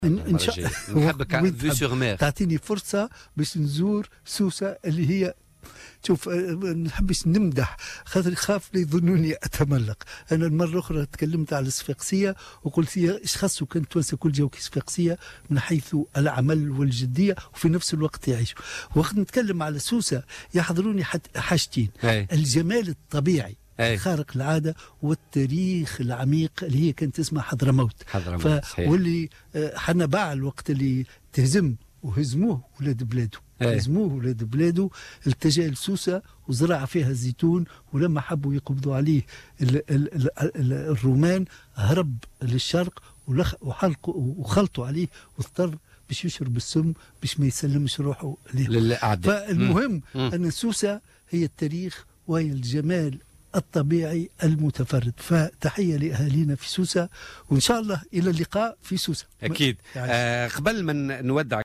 وصف السياسي نجيب الشابي اليوم مدينة سوسة بأنها التاريخ العميق والجمال الطبيعي المتفرّد، بحسب تعبيره في برنامج "بوليتيكا" على "الجوهرة أف أم".